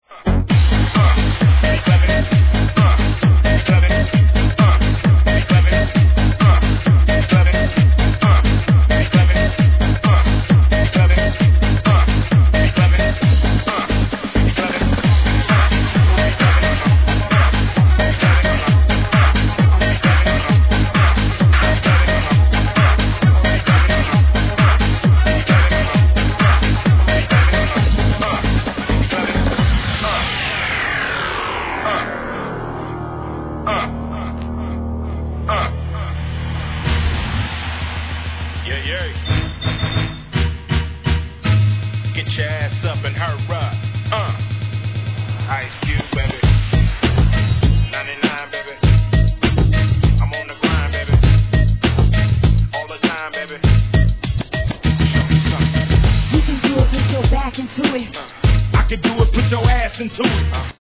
1. G-RAP/WEST COAST/SOUTH
強烈な四つ打ちREMIX収録のUK盤。